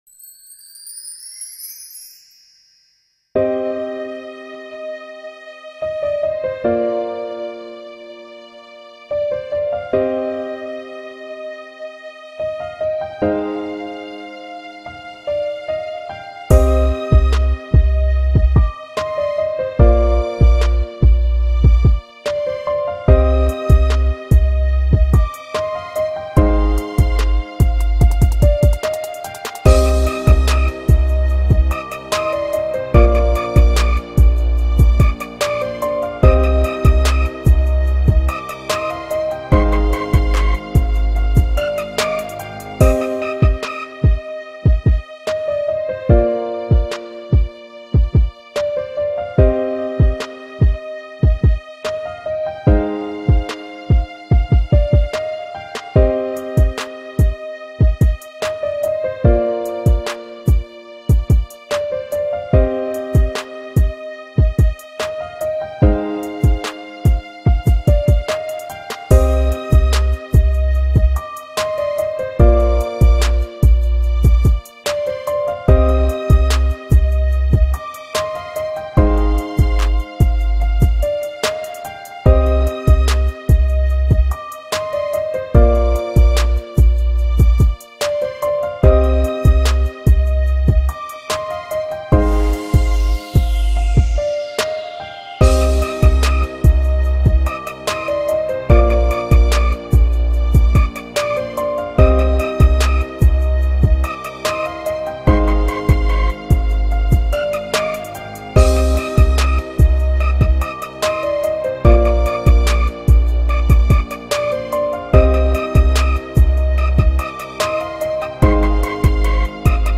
შესვლა  HipHop,Rap Instrumental